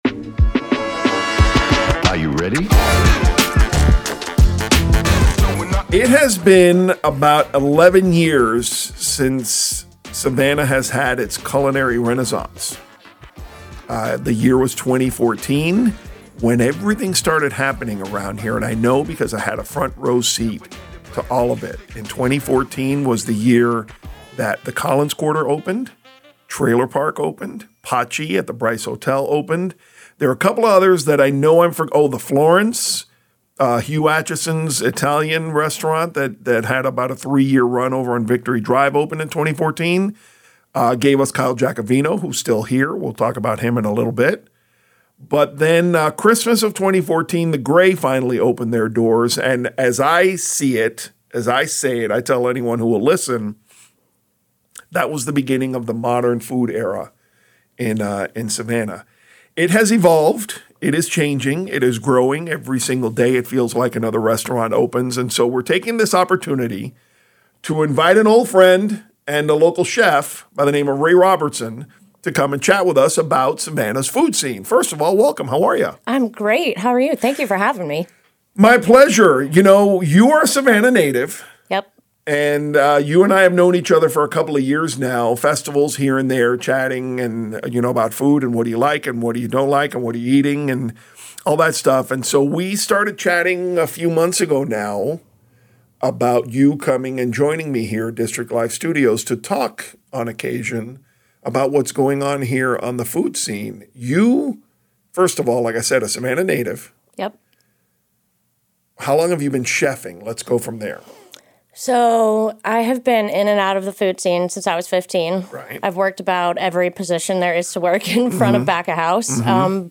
Podcast: A candid conversation about Savannah's food scene - Eat It & Like It